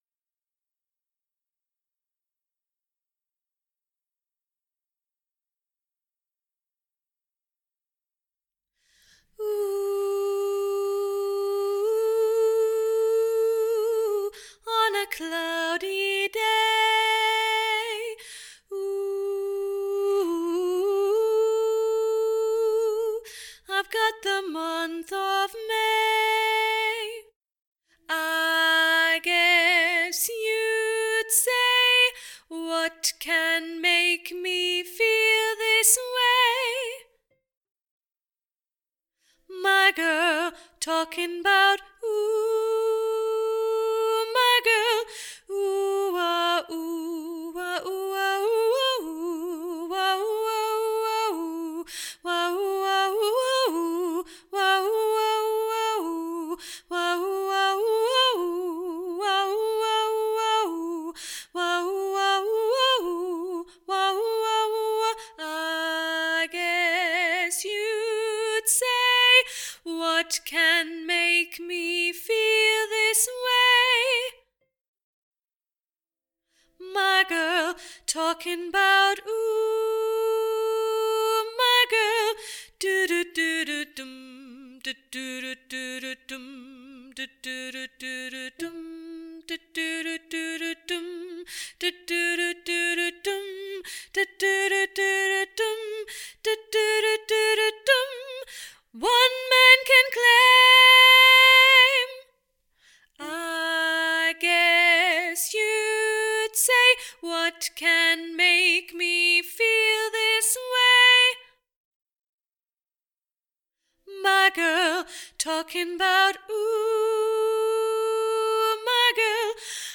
My Girl Sop